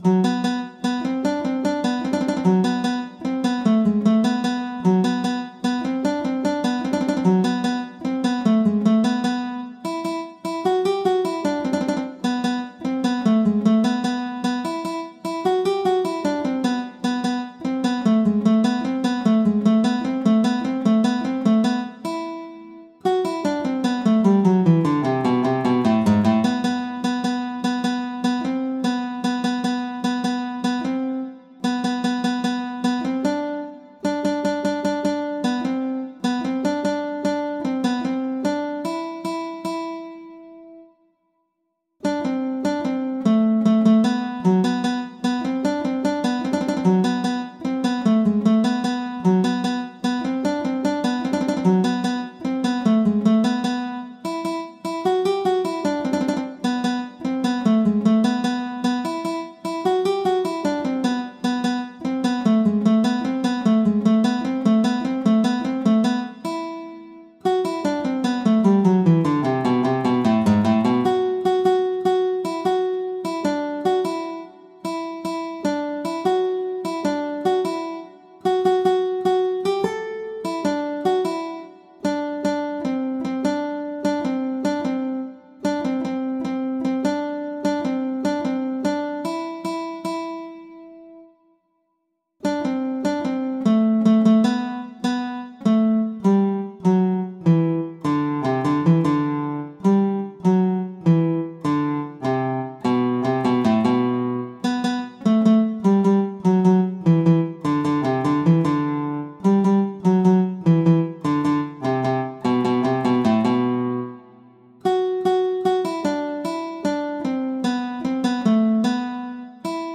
سطح : ساده
شامل » ملودی تبلچر آکورد و نت